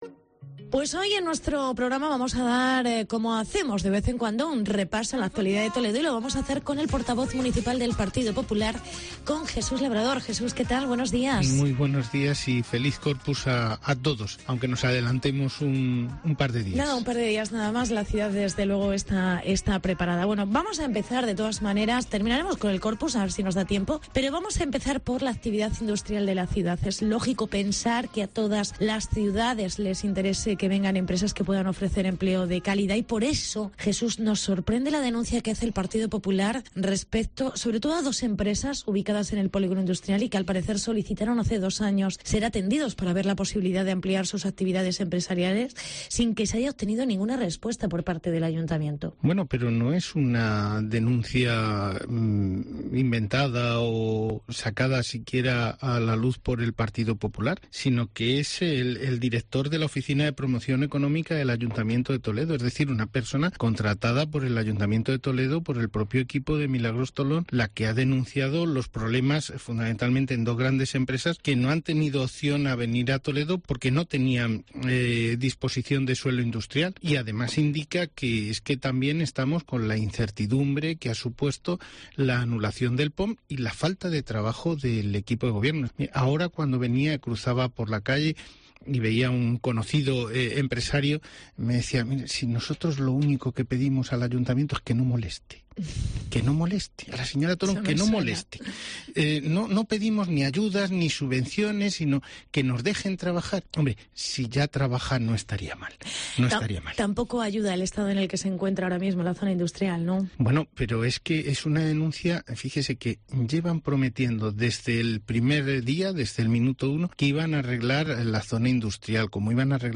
Entrevista con el portavoz municipal del PP en Toledo: Jesús Labrador